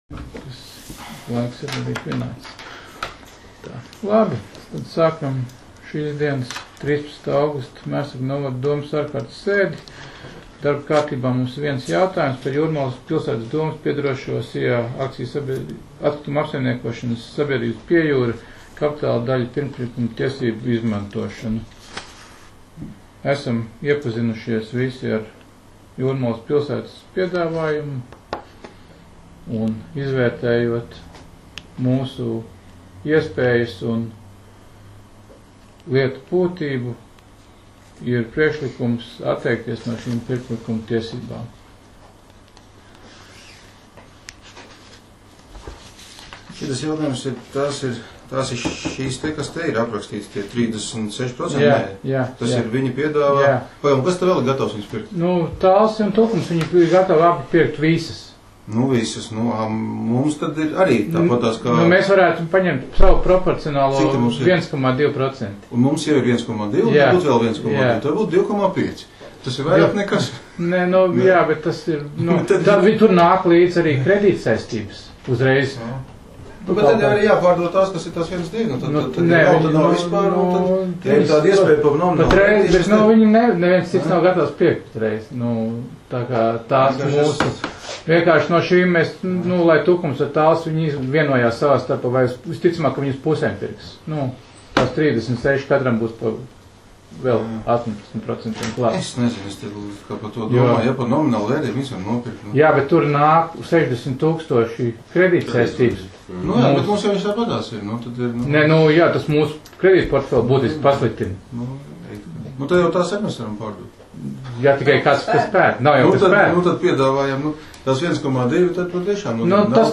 Mērsraga novada domes sēde 13.08.2019.